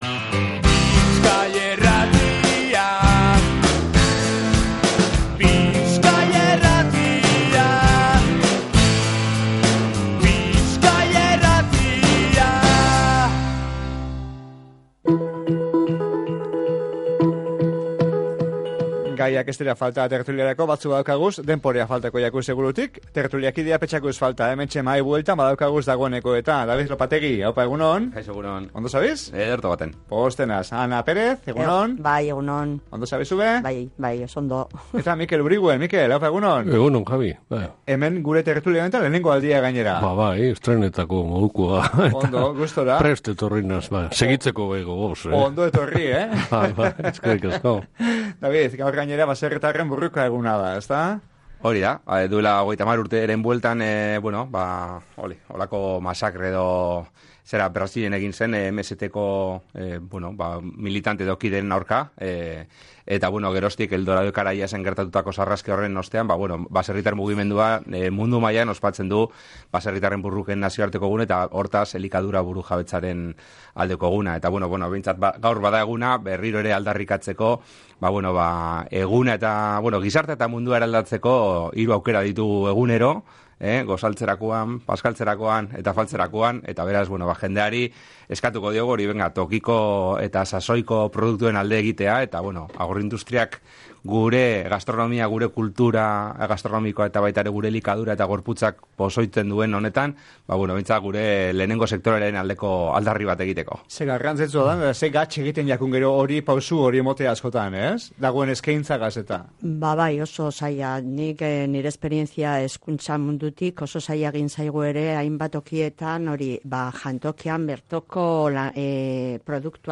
GIZARTE-GAIEN-TERTULIA-1.mp3